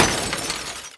CraftFail.wav